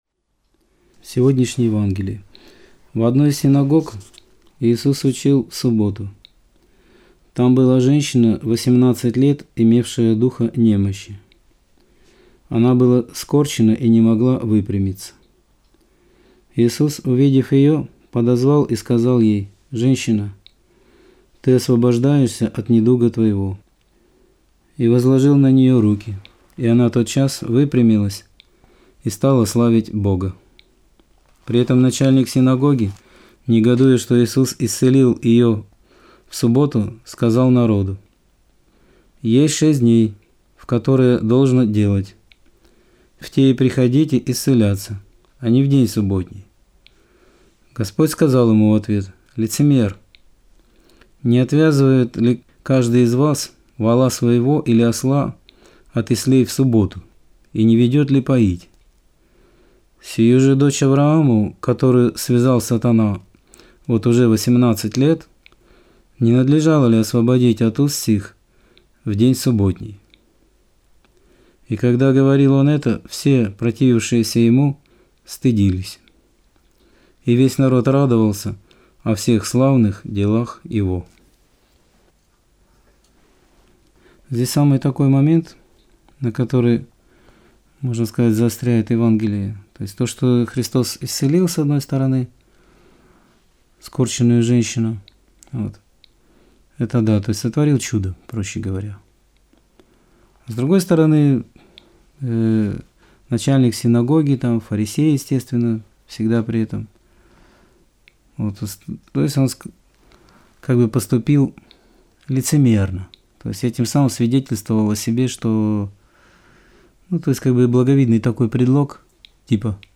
Аудио-проповедь 12.12.2021 | Христианская Церковь